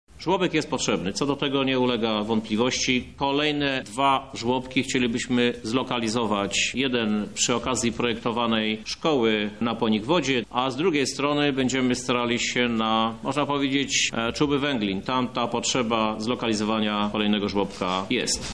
O planach miasta i potrzebach mieszkańców mówi prezydent Lublina Krzysztof Żuk: